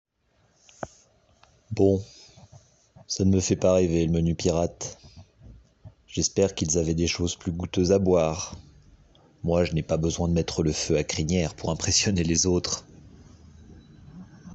voix personnage animation